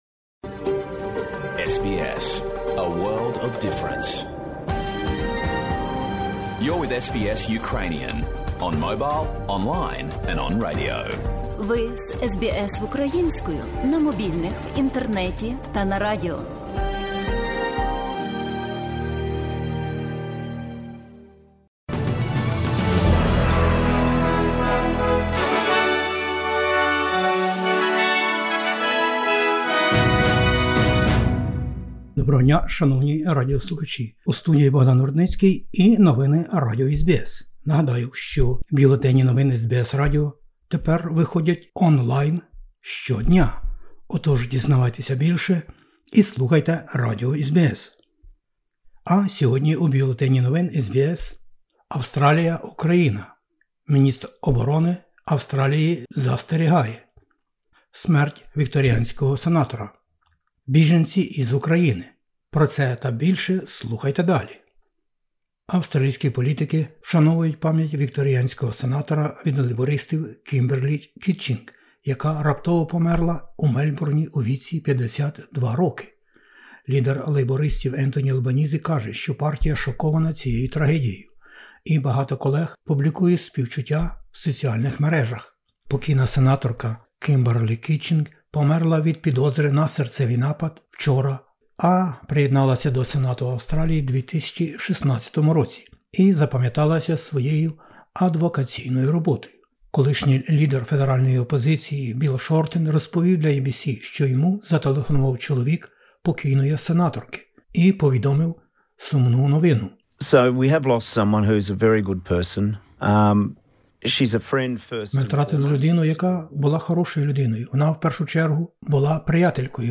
SBS новини українською - 11/03/2022